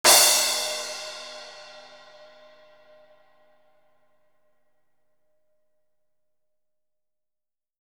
CRASH.wav